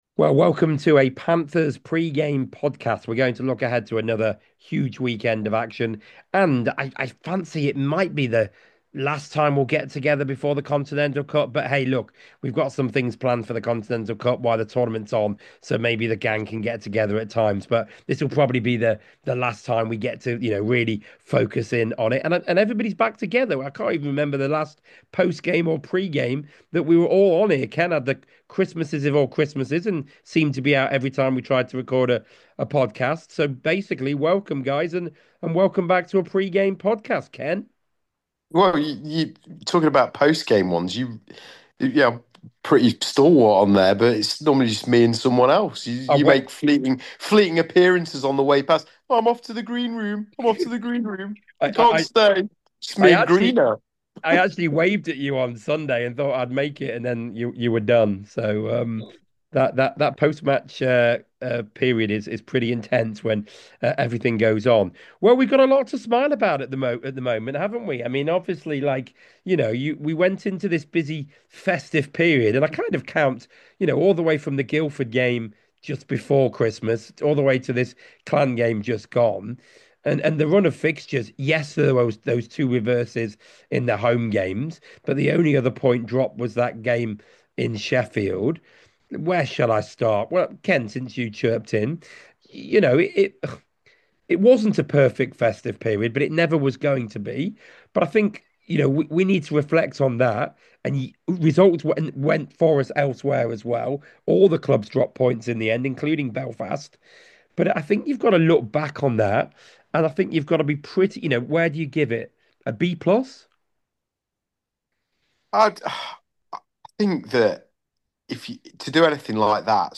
The five guys look back on the four-point weekend, the festive season as a whole and ahead to the next two matches.